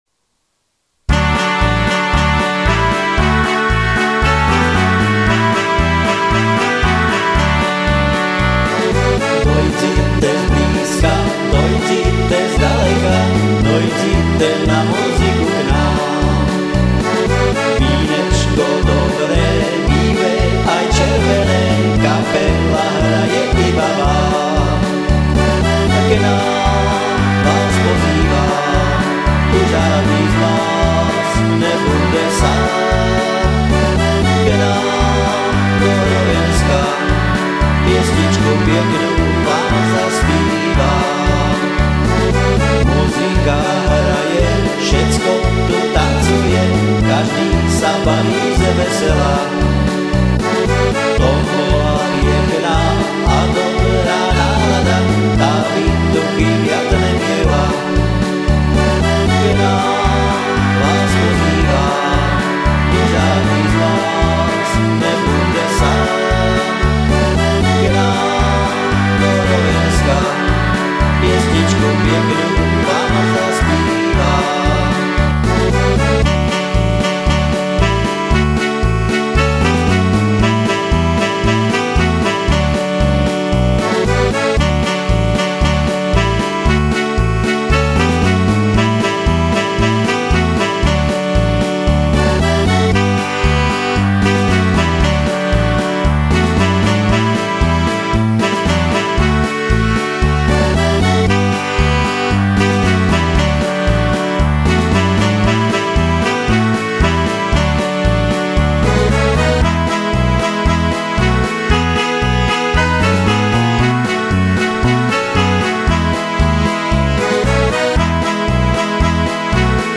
Som amatérsky muzikant, skladám piesne väčšinou v "záhoráčtine" a tu ich budem prezentovať.